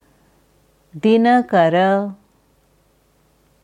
Sanskrit Dinakara korrekte Aussprache anhören
Es wird in der indischen Devanagari Schrift geschrieben दिनकर, in der IAST Umschrift dina-kara. Hier kannst du hören, wie man ganz korrekt das Wort Dinakara ausspricht.